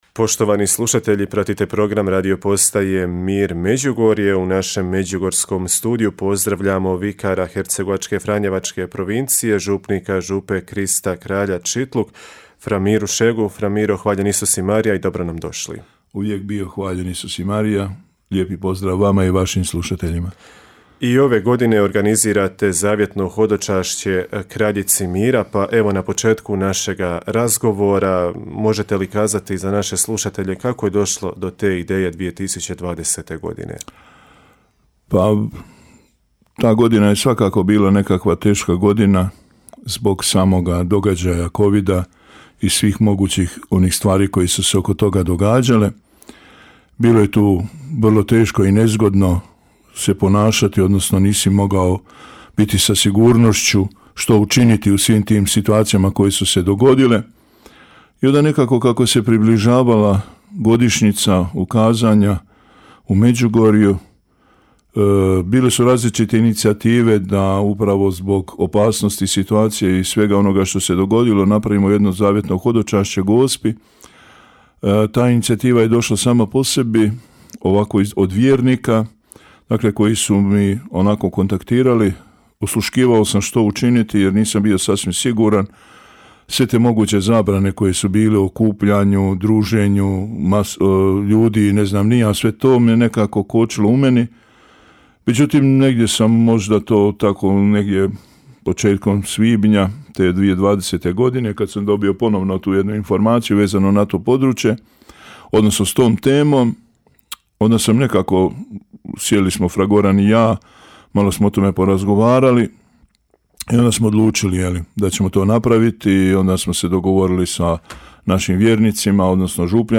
Propovijed